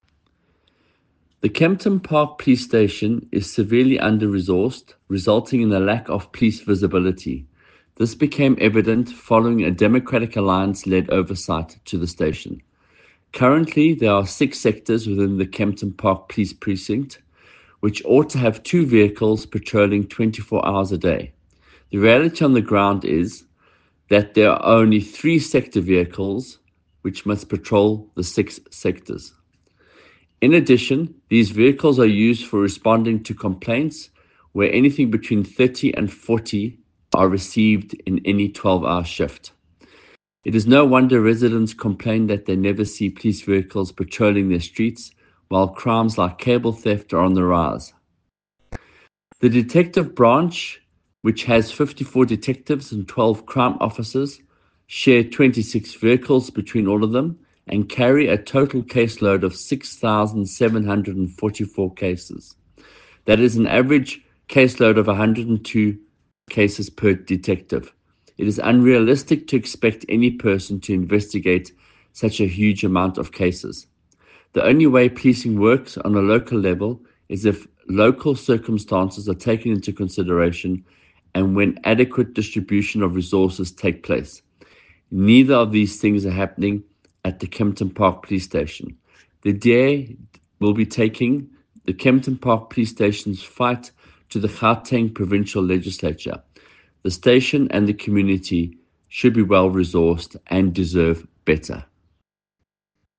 Note to Editors: Please find an English soundbite by Michael Waters MPL
Michael-Waters_ENG_DA-oversight-finds-kempton-park-saps-wanting-1.mp3